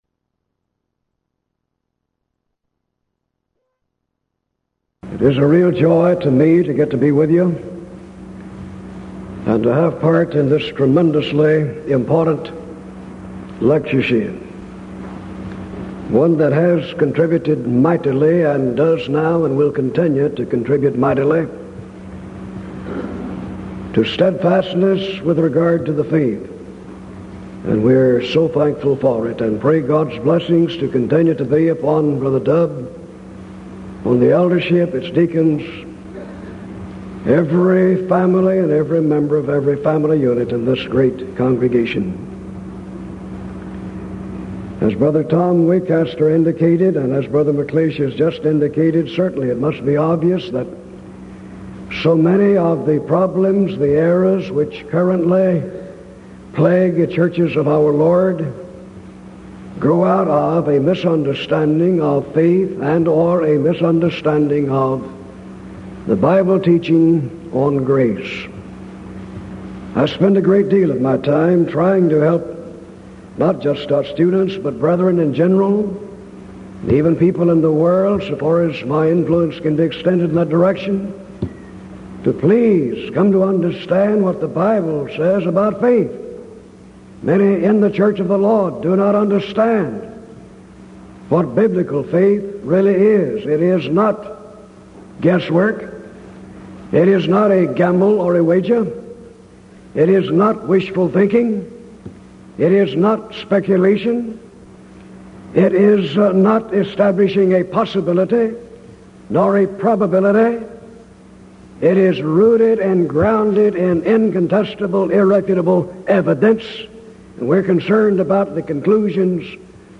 Title: Discussion Forum
Event: 1986 Denton Lectures Theme/Title: Studies in Galatians